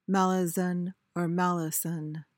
(MAL-uh-zuhn/suhn)
malison.mp3